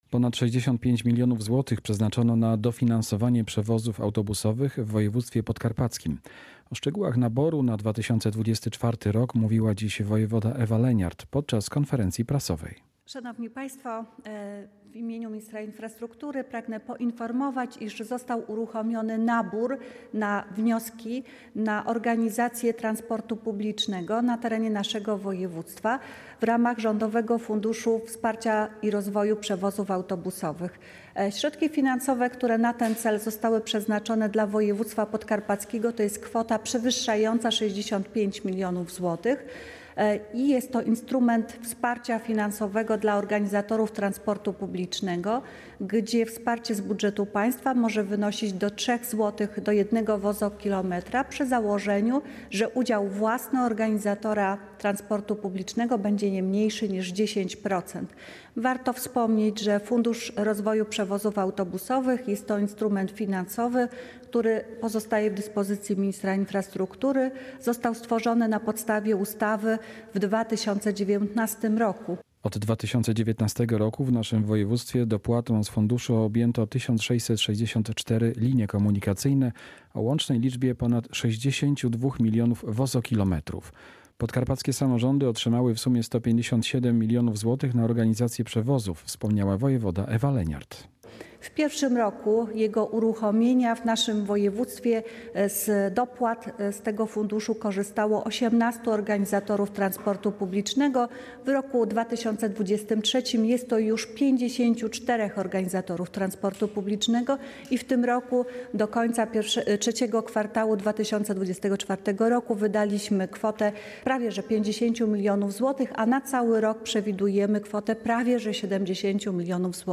Relacja
konferencja-woj.19.10.mp3